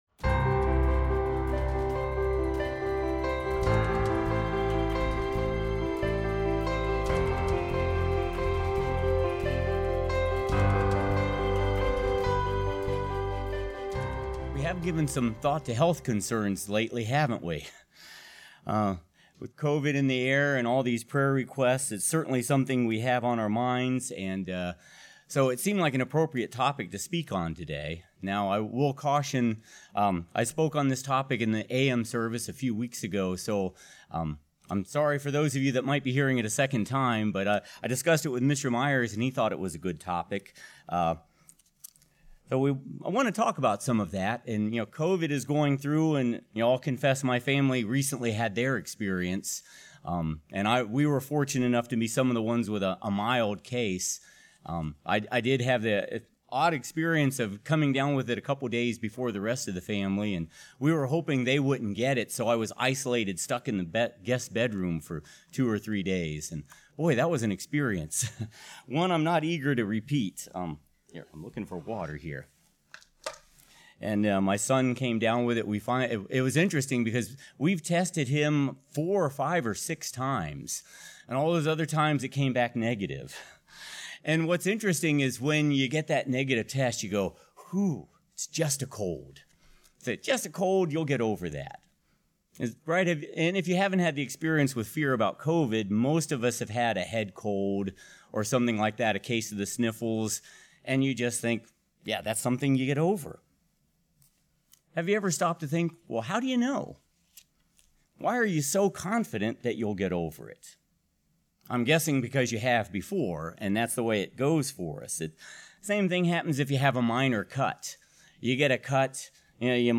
The Bible shows that God is a healing God, who wants to heal His people. This message will consider biblical instruction and examples pertaining to divine healing.